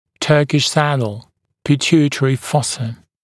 [‘tɜːkɪʃ ‘sædl] [pɪ’tjuːɪtərɪ ‘fɔsə][‘тё:киш ‘сэдл] [пи’тйу:итэри ‘фосэ]турецкое седло